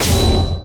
cora_guardtower_critical.wav